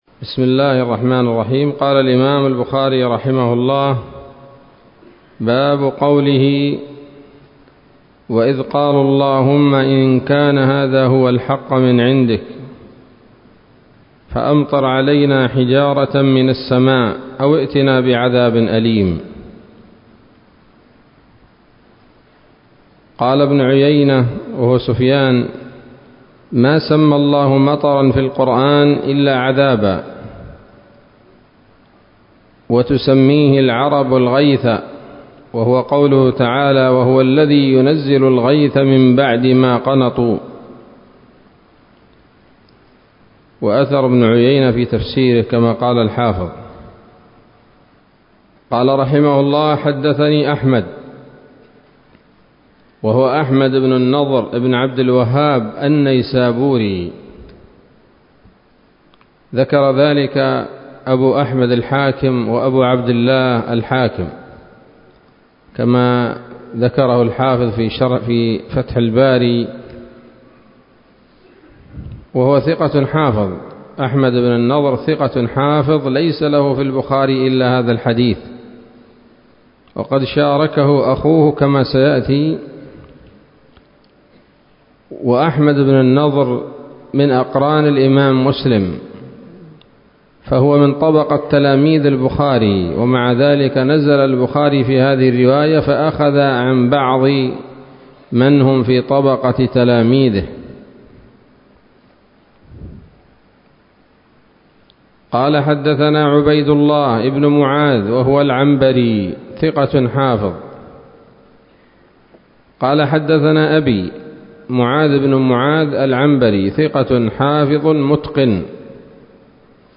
الدرس الرابع عشر بعد المائة من كتاب التفسير من صحيح الإمام البخاري